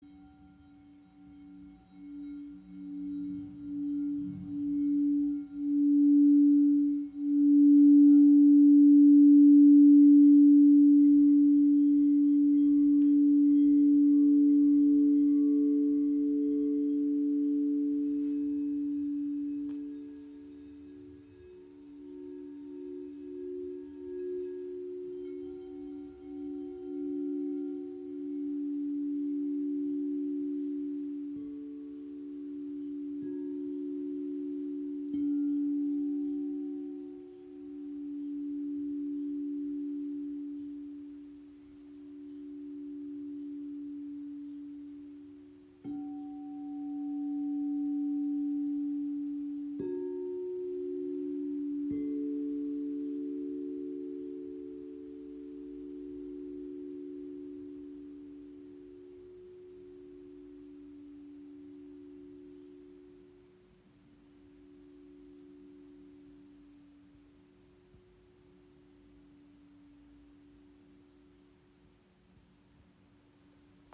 Kristallklangschalen D – F# – A | Klangheilung & Herzöffnung • Raven Spirit
Klangbeispiel
In Verbindung mit Kristallklangschalen entsteht ein lichtvoller Klangraum – ideal für therapeutische Arbeit, Meditation und energetische Balance.
Lichtvoller, zentrierender Klang ideal für Klangheilung.
Weiterlesen Klangbeispiel Kristallklangschalen D – F# – A in 432 Hz (Handyaufnahme)